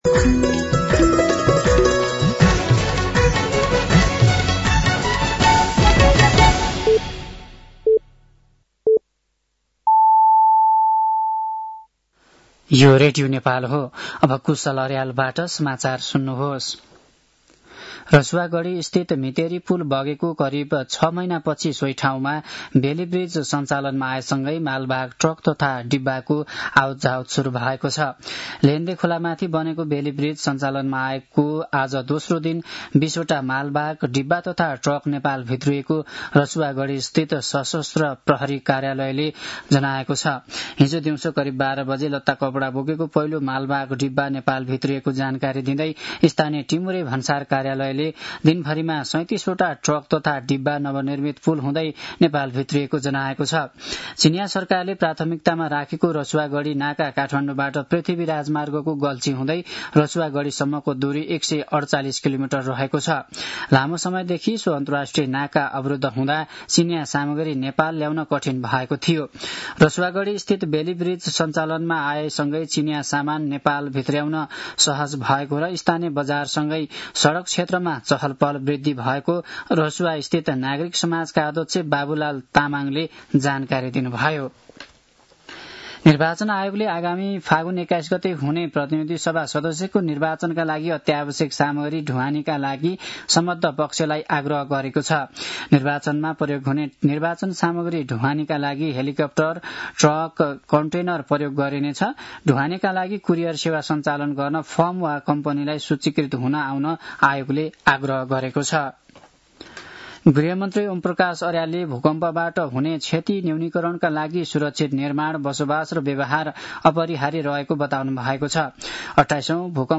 साँझ ५ बजेको नेपाली समाचार : १८ पुष , २०८२